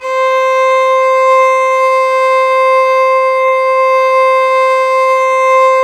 Index of /90_sSampleCDs/Roland - String Master Series/STR_Violin 4 nv/STR_Vln4 no vib
STR VLN BO0C.wav